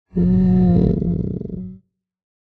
minecraft / sounds / mob / sniffer / idle8.ogg